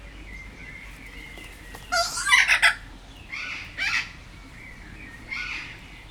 Directory Listing of /_MP3/allathangok/jaszberenyizoo2016_professzionalis/kea/